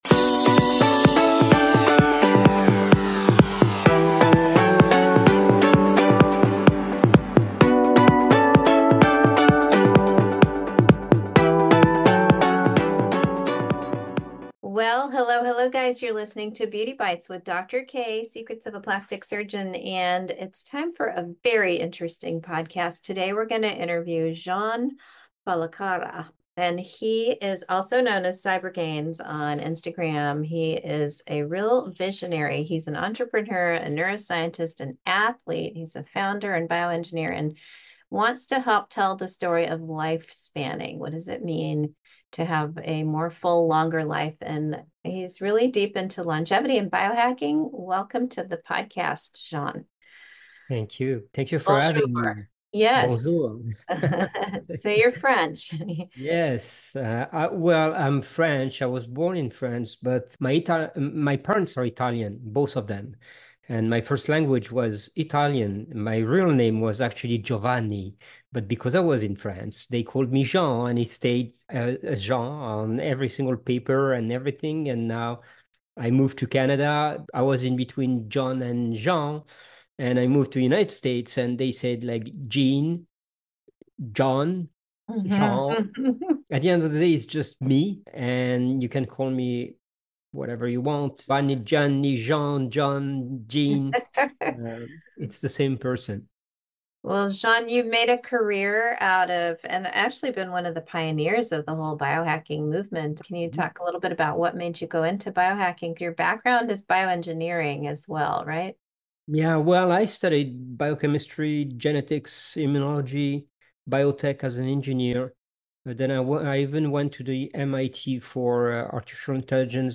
Tune in for an exciting conversation on lifespanning.